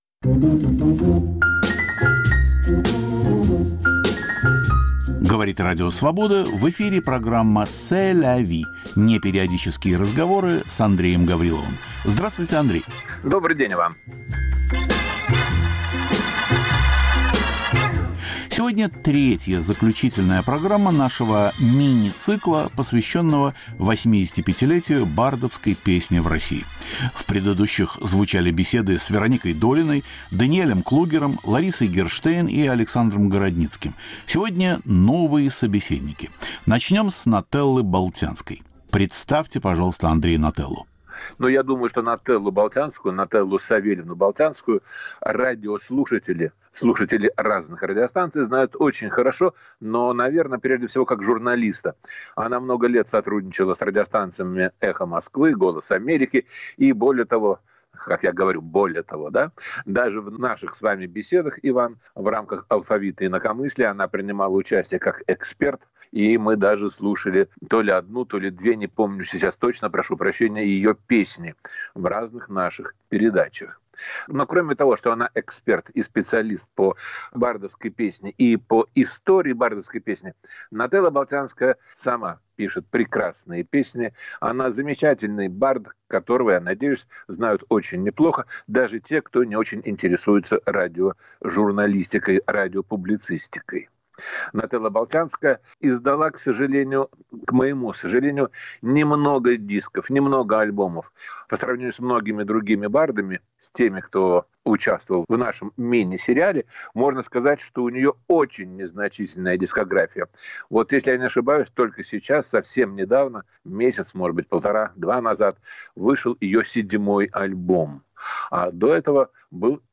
Два собеседника - Нателла Болтянская и Юлий Ким.